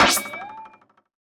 otherHighJump.wav